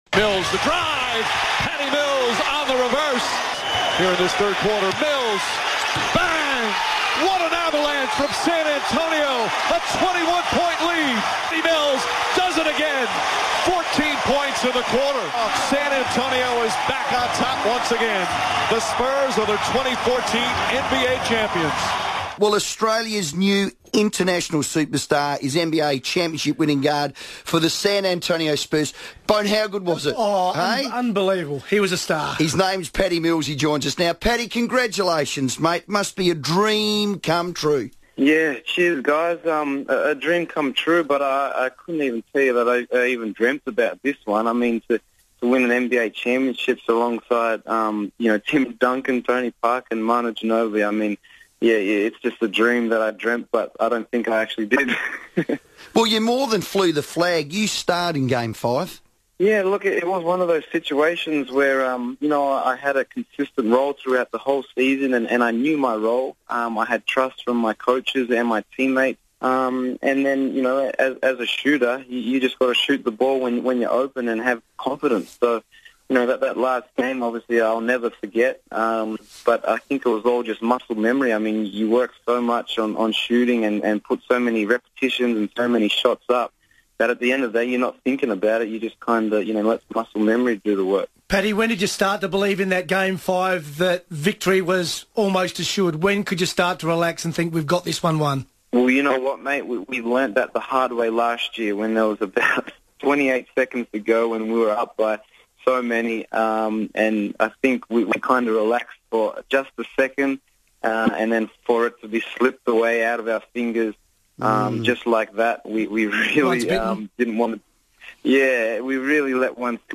Crows ambassador and NBA champ Patty Mills talks to FIVEaa